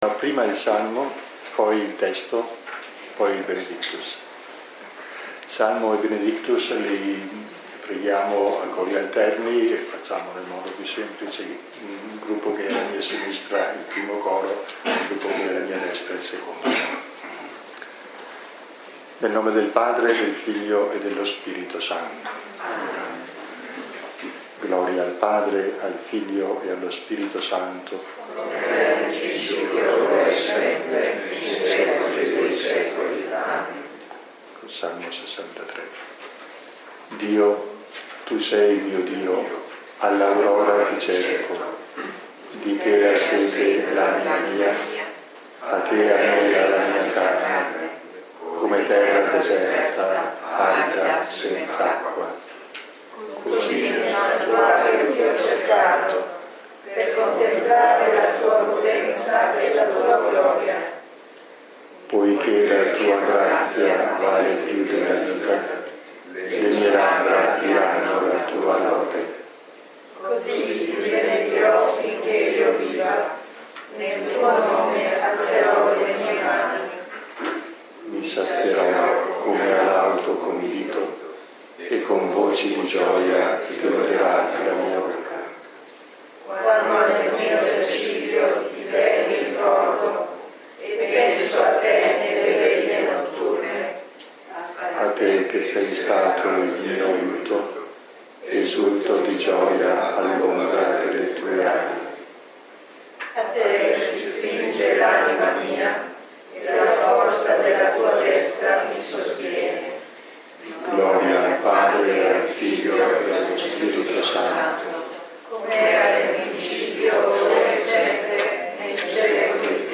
Lectio 2 – 18 novembre 2018 – Antonianum – Padova